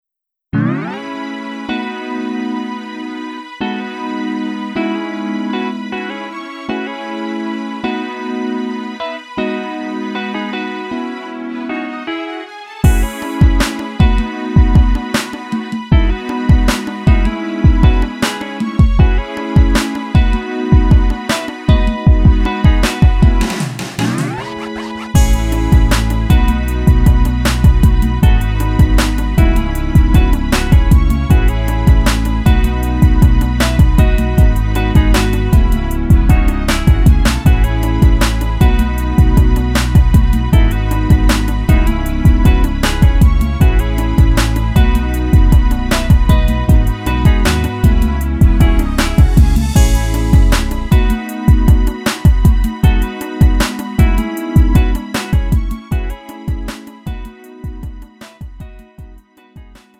음정 원키 3:59
장르 구분 Lite MR